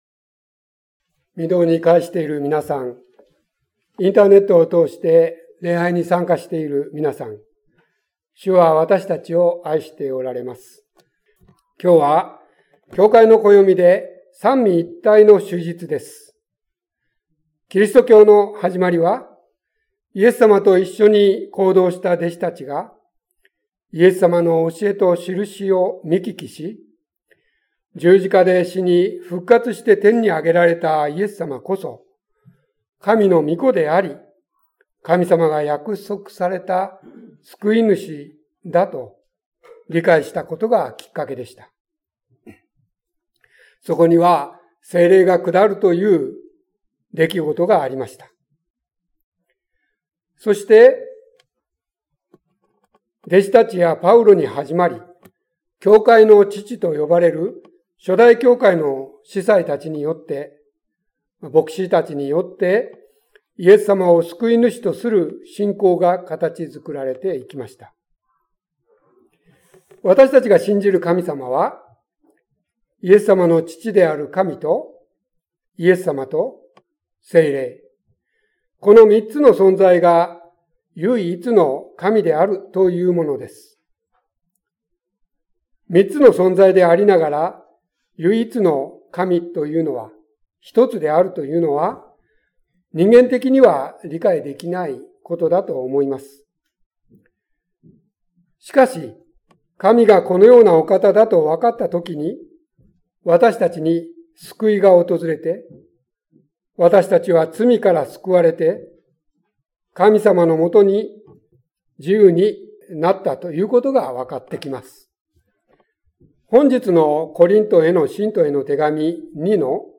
6月4日三位一体主日礼拝説教「愛、恵み、交わり」